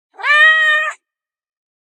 Realistic Cat Meow Sound Effect
Description: Realistic cat meow sound effect. A classic meow from a house cat. The cat is meowing.
Animal sounds.
Realistic-cat-meow-sound-effect.mp3